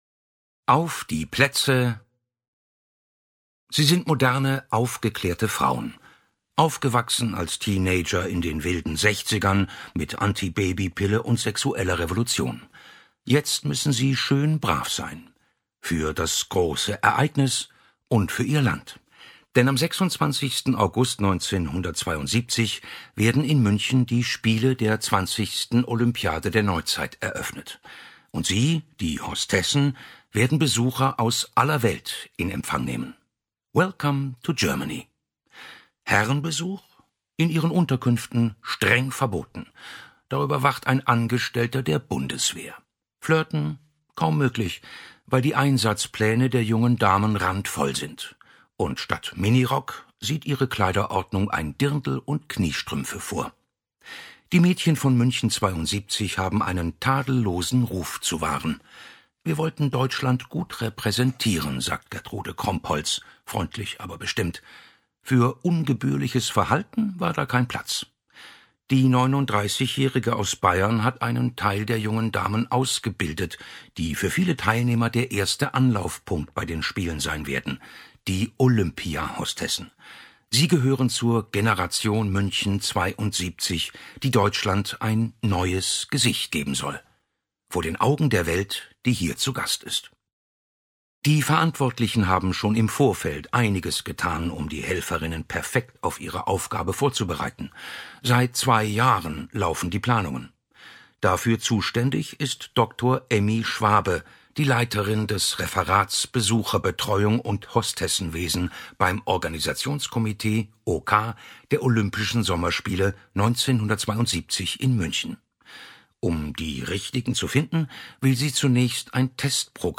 Hörbuch: München 72.
München 72. Ein deutscher Sommer Ungekürzte Lesung